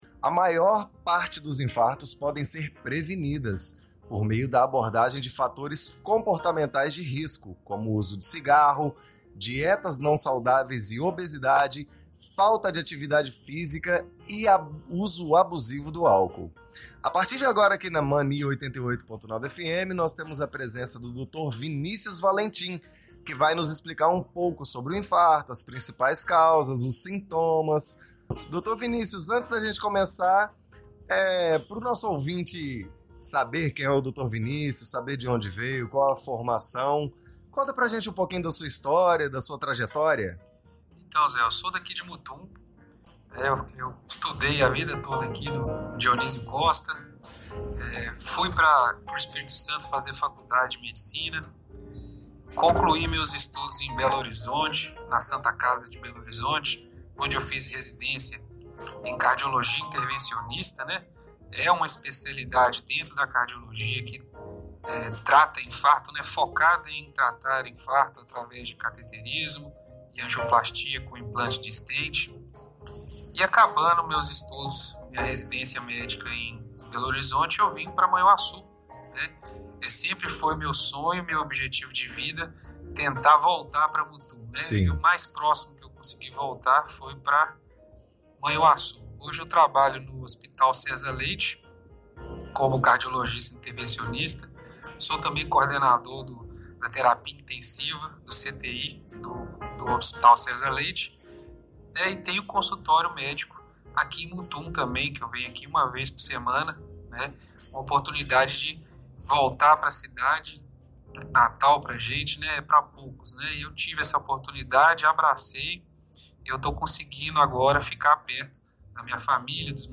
Entrevista
entrevista ao vivo